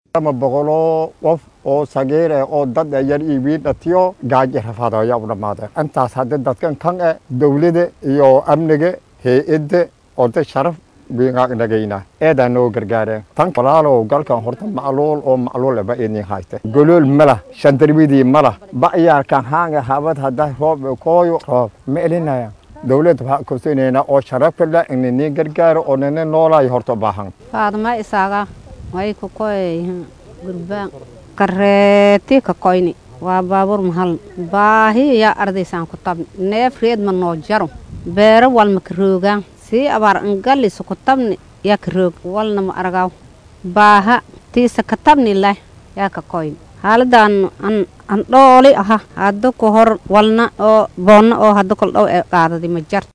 Sidoo kale waxaa jiro dadka kale oo ka soo barakacay degmooyin ka tirsan gobollada dalka Soomaaliya kuwaas oo ay abaartu aad saameysay. Qaar ka mid ah dadkaasi ayaa la hadlay warbaahinta, iyagoona ka warbixiyay xaaladda nolosha ay ku jiraan iyo waliba sidoo kale waxa ay u baahan yihiin in loo qabto.